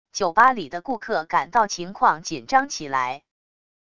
酒吧里的顾客感到情况紧张起来wav音频